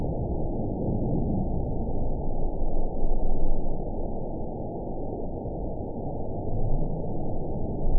event 920711 date 04/04/24 time 17:36:18 GMT (1 year ago) score 9.15 location TSS-AB07 detected by nrw target species NRW annotations +NRW Spectrogram: Frequency (kHz) vs. Time (s) audio not available .wav